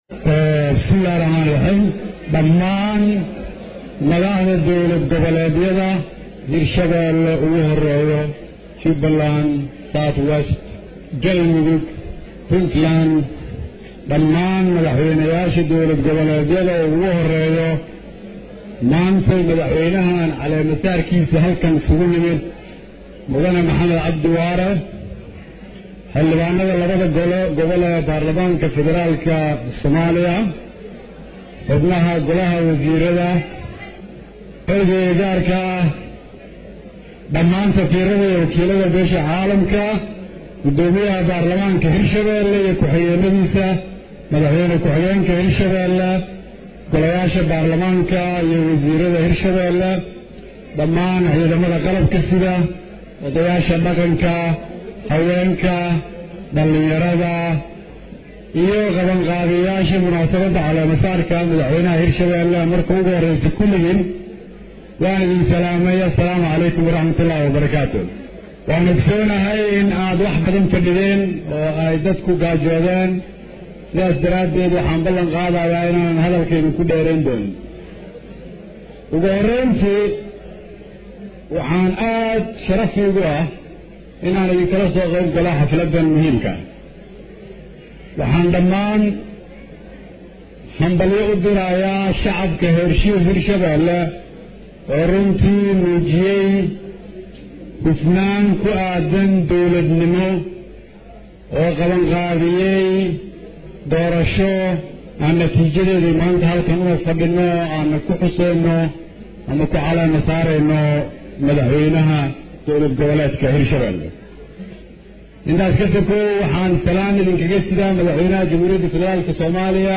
Dhagayso: Khudbadii Ra’iisul Wasaare Kheyre uu ka jeediyay Caleemasaarkii Waare ee Jowhar
24 Okt 2017 (Puntlandes) Ra’iisal Wasaaraha Soomaaliya Xasan Cali Kheyre ayaa shalay khudbad uu ka jeediyay munaasabadii lagu caleema saarayay Madaxweynaha dowlad goboleedka Hirshabelle waxa uu ka hadlay ajandayaasha loogaga hadlayo kulanka uu Madaxweyne Farmaajo ku martiqaaday Madaxda dowlad goboleedyada dalka.
Dhagayso-khudbada-Raisal-Wasaare-Kheyre-ee-caleemasaarka-Madaxweyne-Waare.mp3